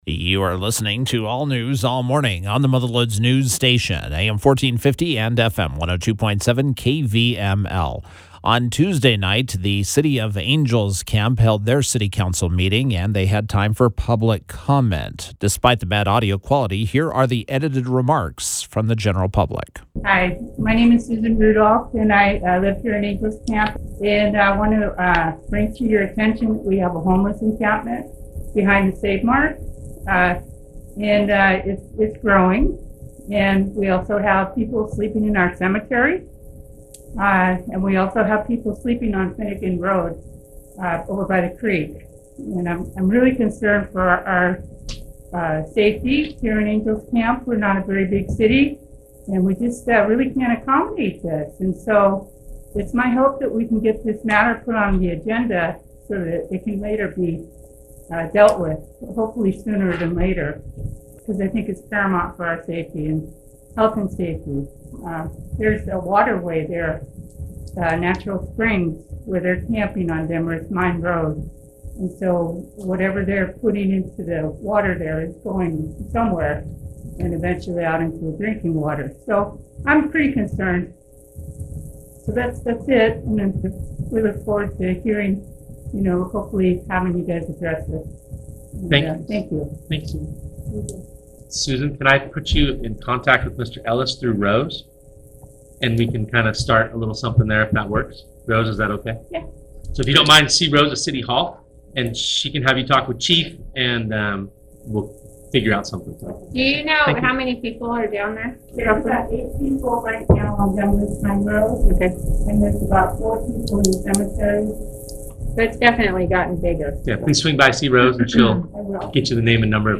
During Tuesday’s Angels Camp City Council meeting, there was time set aside for public comments.